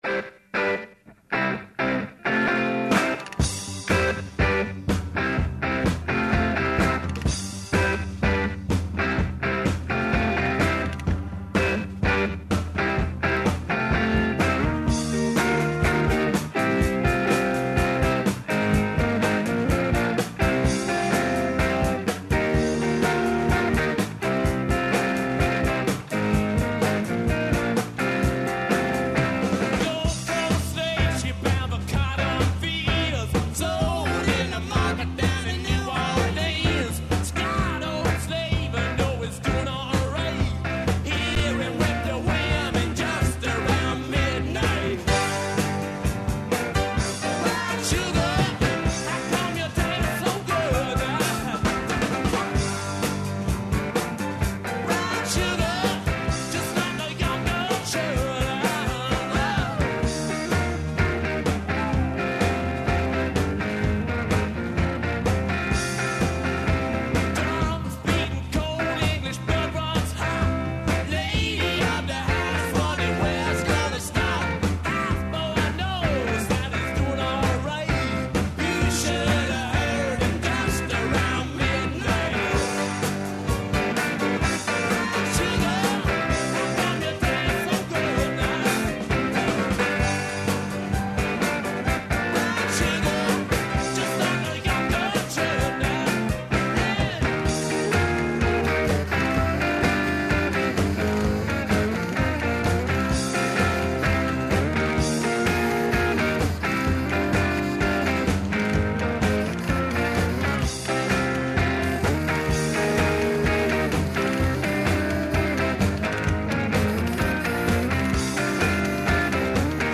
Са студентима разговарамо о њиховим омиљеним ауторима, делима и жанровима, а исто питање постављамо и вама.
преузми : 20.27 MB Индекс Autor: Београд 202 ''Индекс'' је динамична студентска емисија коју реализују најмлађи новинари Двестадвојке.